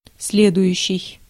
Ääntäminen
US RP : IPA : /ˈfɒləʊɪŋ/ GenAm: IPA : /ˈfɑloʊɪŋ/